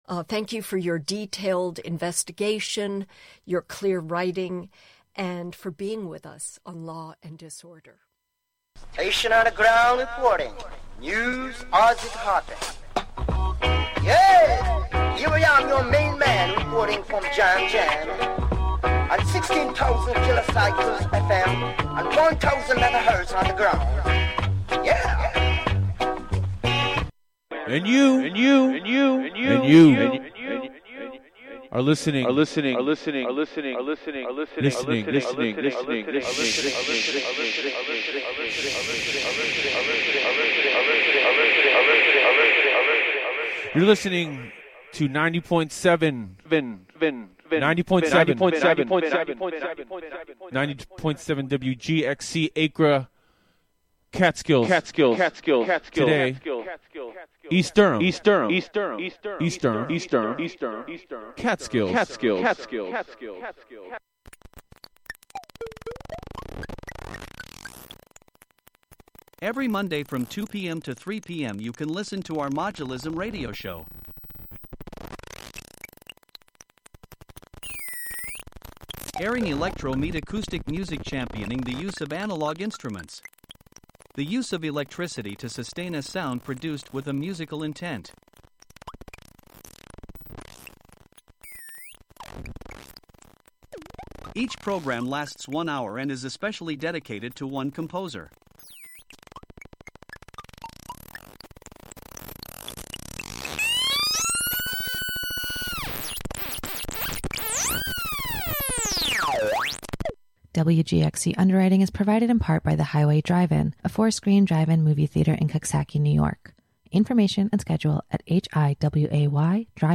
"Suddering Words" is a monthly improvised mashup of various radio show formats from rock jock to radio drama to NPR to old time mysteries and everything in between. It is unattainable gibberish in its perfect form. There will be songs, news, stories, manipulated cassettes, and some in-studio visits by demons AND angels, and it all takes place in a village of airwaves dominated by slittering sounds and suddering words.